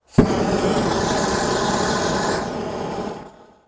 На этой странице собраны реалистичные звуки огнемета — от гула воспламенения до рева пламени.
Звук пламени огнемета